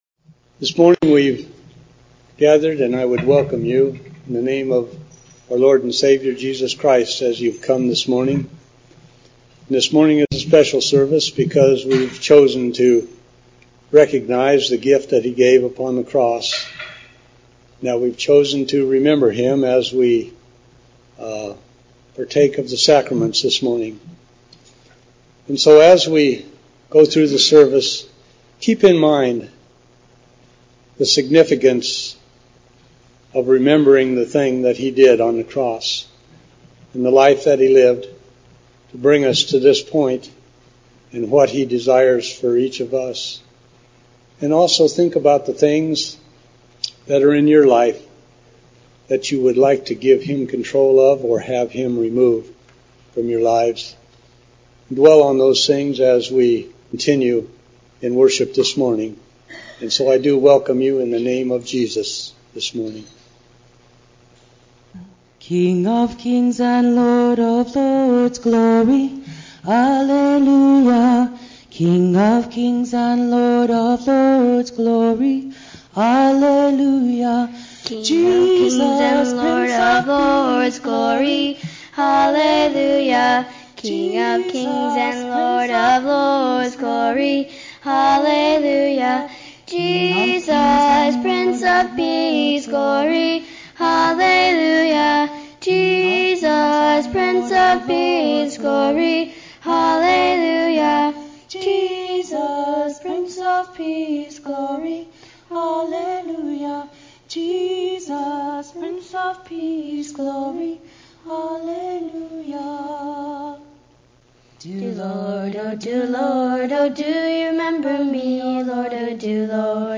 Audio-Full Service